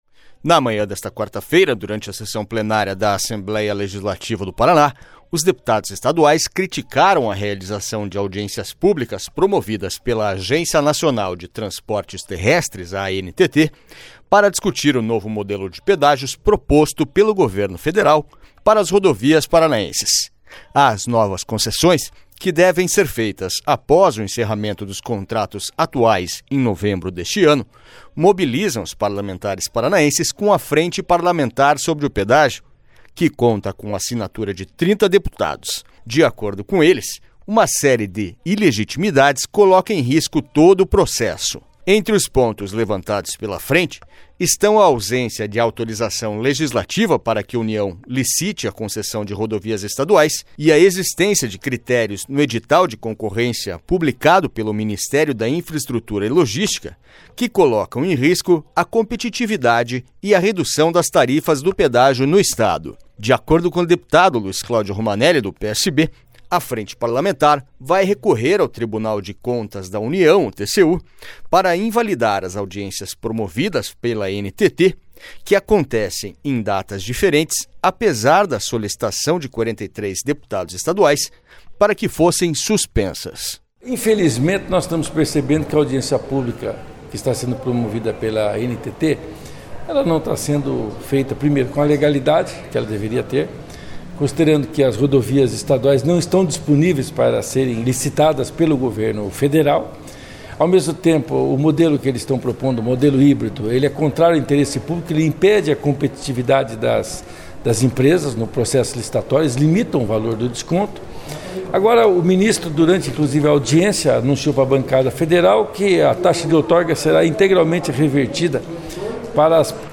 Na manhã desta quarta-feira, durante a sessão plenária da Assembleia Legislativa do Paraná, os deputados estaduais criticaram a realização de audiências públicas promovidas pela agencia Nacional de Transportes Terrestres (ANTT) para discutir o novo modelo de pedágios proposto pelo Governo Federal para as rodovias paranaenses.
SONORA LUIZ CLÁUDIO ROMANELLI